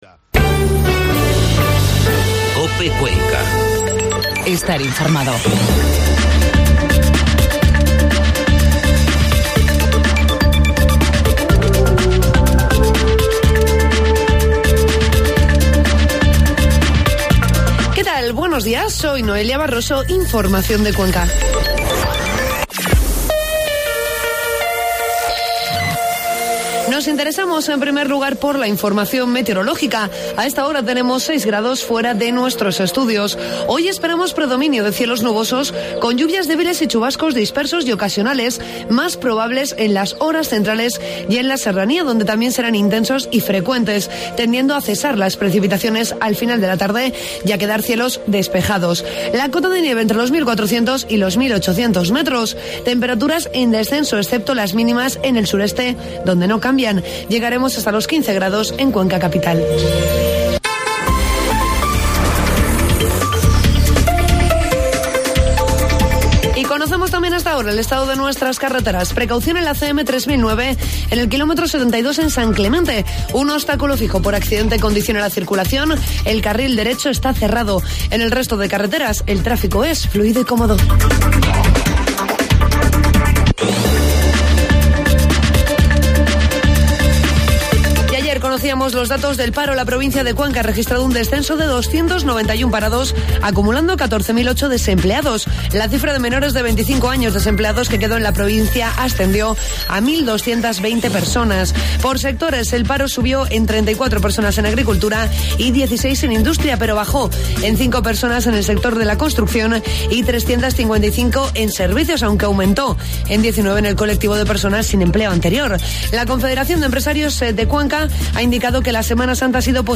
AUDIO: Informativo matinal